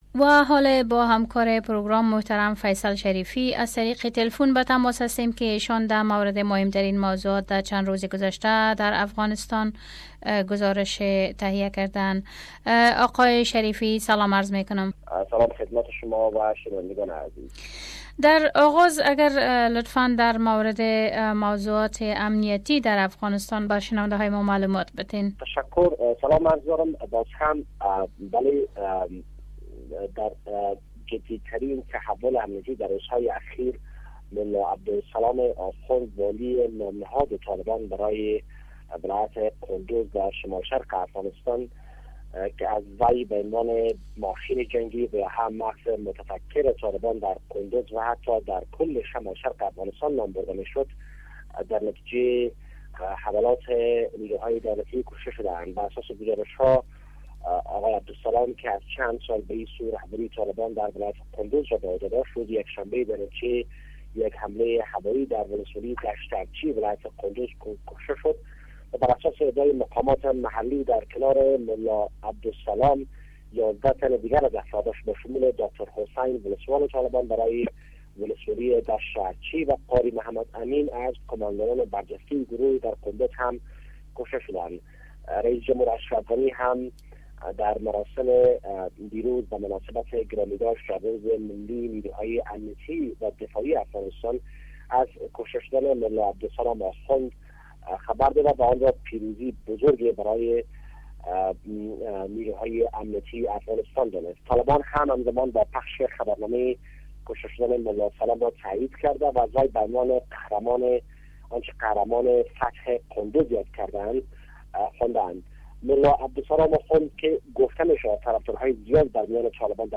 Our Reporter